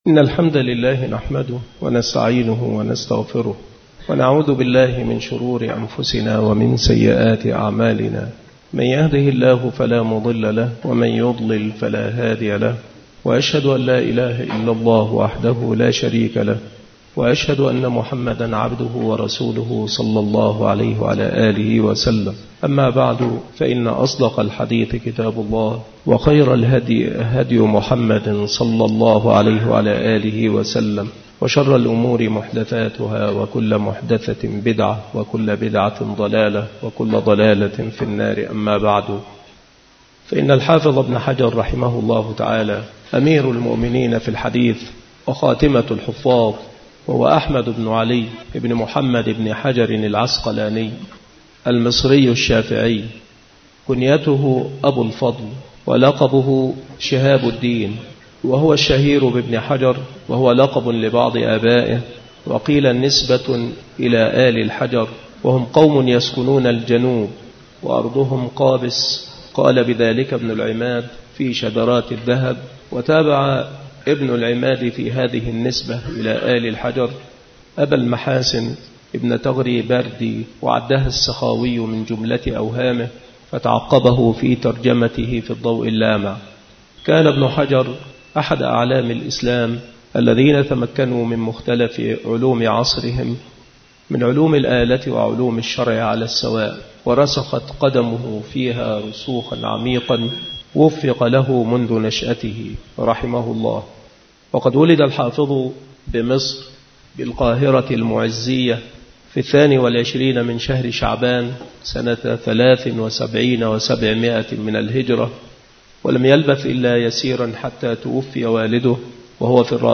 مكان إلقاء هذه المحاضرة بالمسجد الشرقي بسبك الأحد - أشمون - محافظة المنوفية - مصر